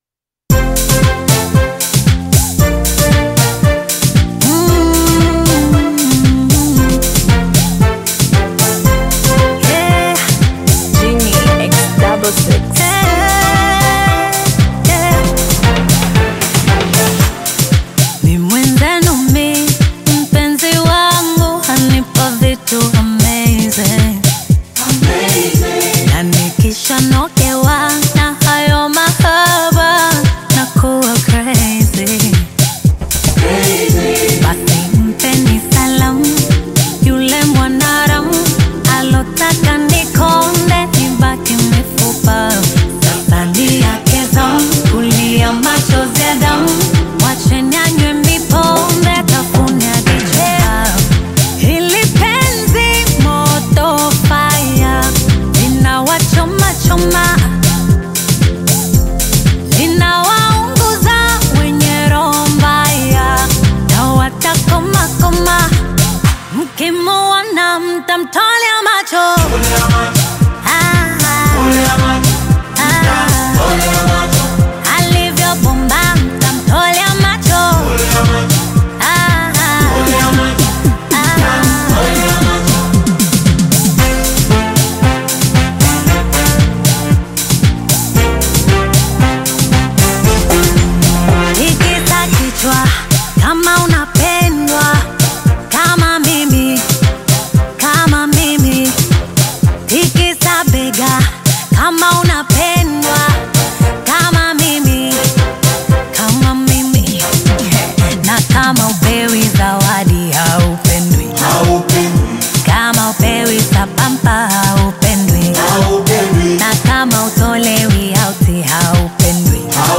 With its catchy flow and uplifting message